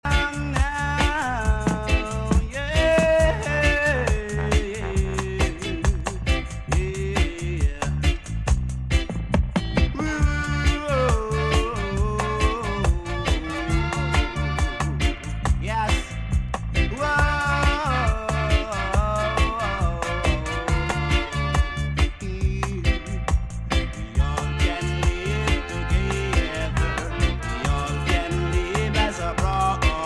Reggae Ska Dancehall Roots